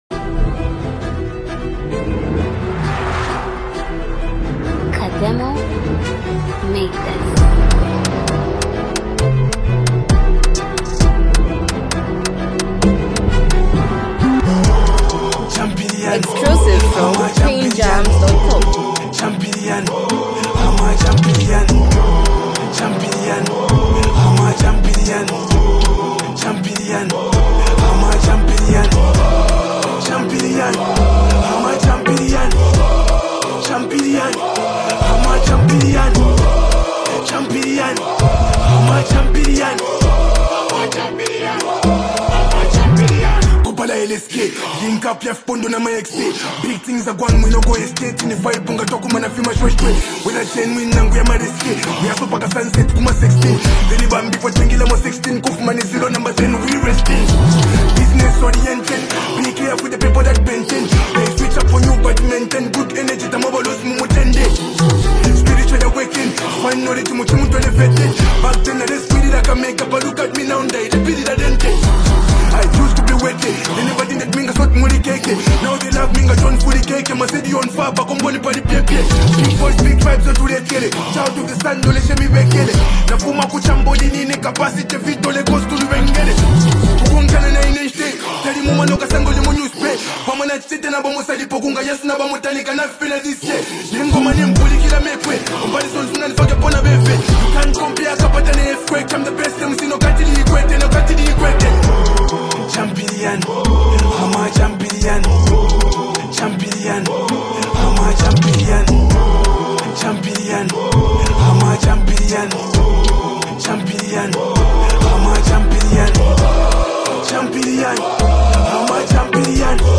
bold and triumphant anthem